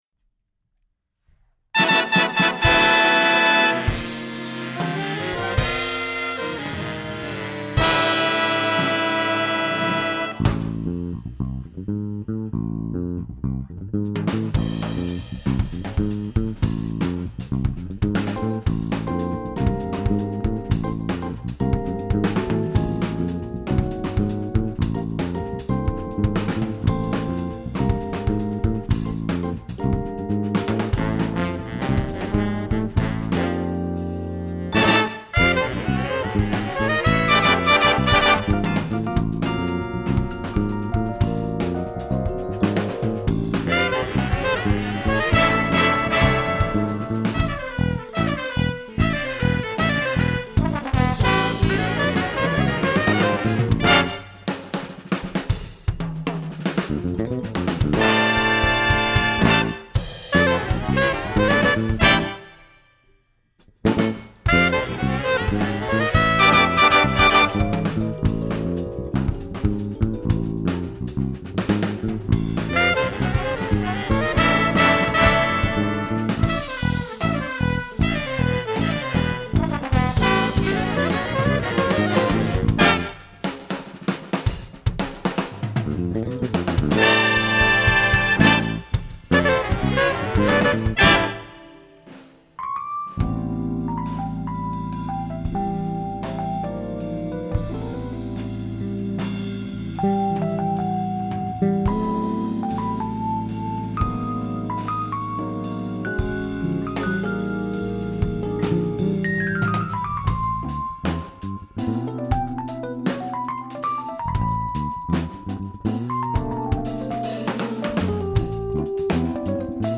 MVHS JAZZ ENSEMBLE PROJECTS BY YEAR
funk Tune
drums
lead trumpet